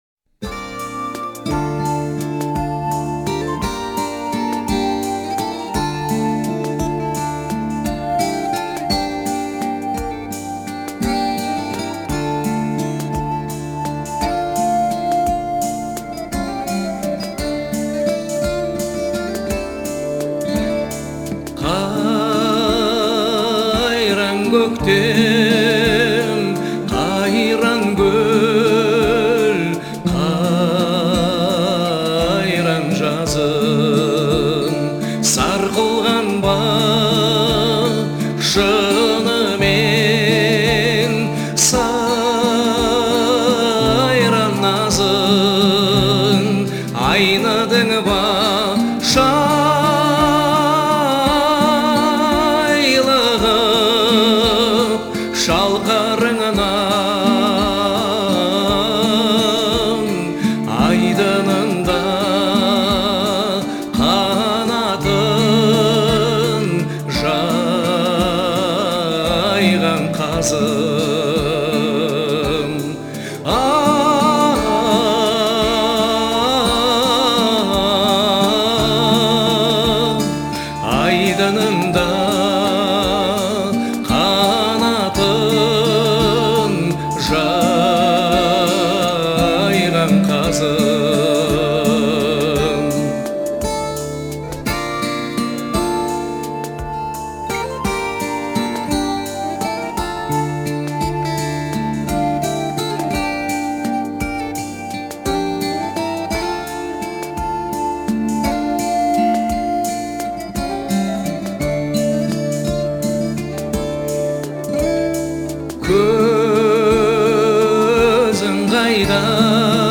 Звучание отличается мелодичностью и душевностью